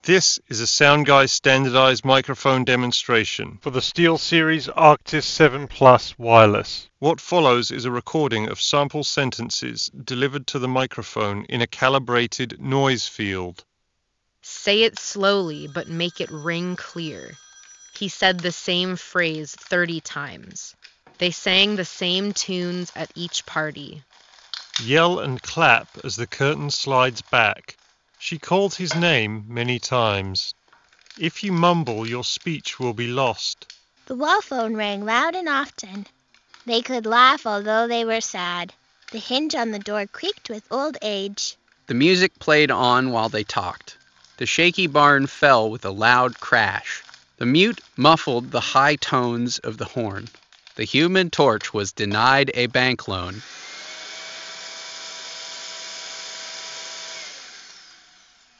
Steelseries-Arctis-7-Wireless_Office-microphone-sample.mp3